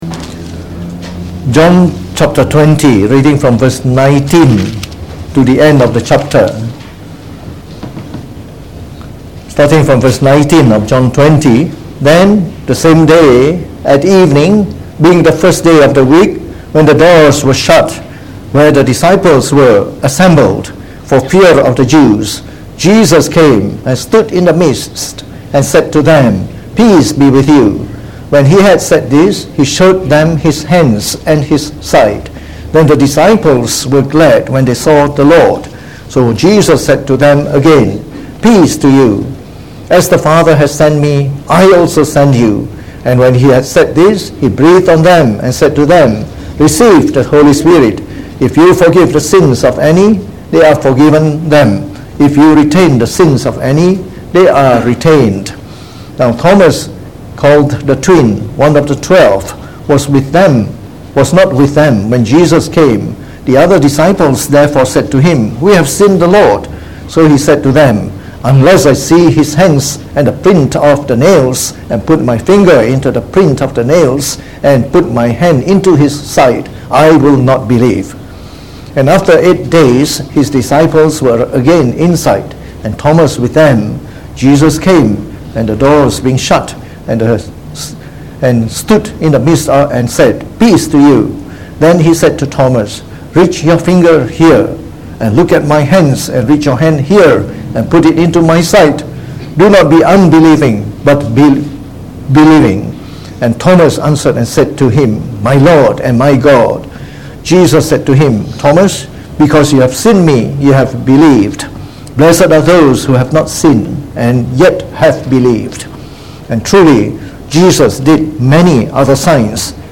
Preached on the 4th of Aug 2019.